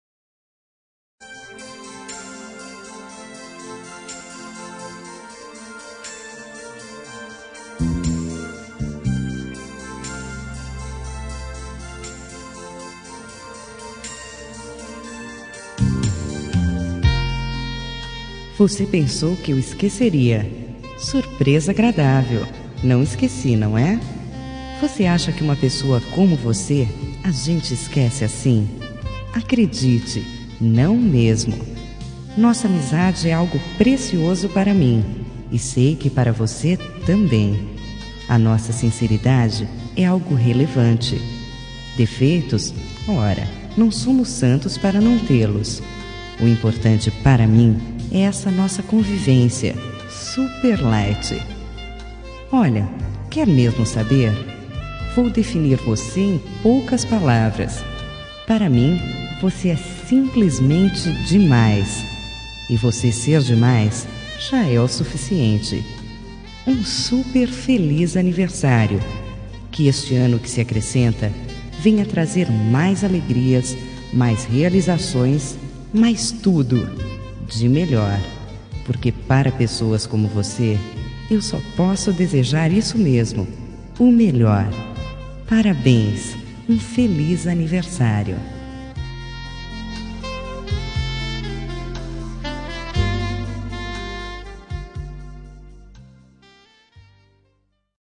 Aniversário de Pessoa Especial – Voz Feminina – Cód: 18810- Achou que eu esqueci?